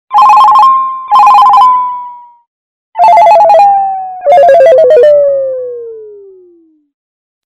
SMS hangok .
crank.mp3